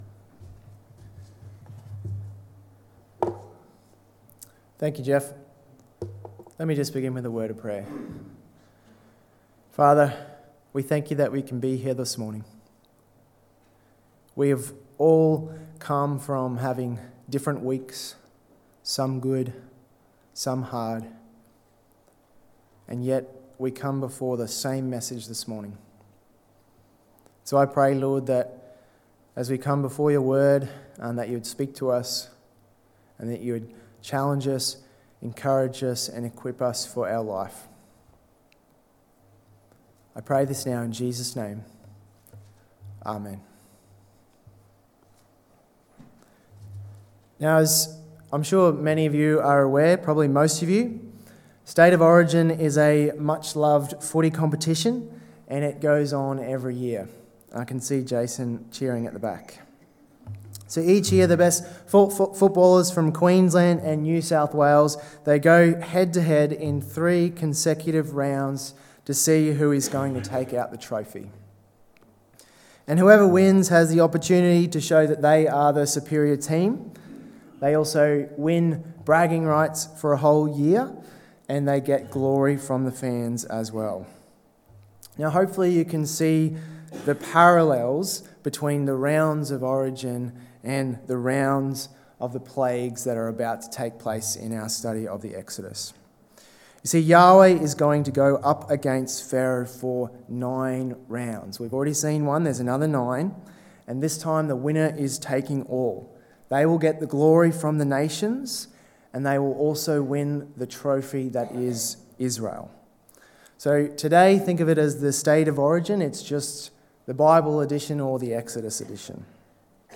Purpose in the Plagues: Knowing God the Easy Way, or the Hard Way (Exodus 8-10 Sermon) 30/07/23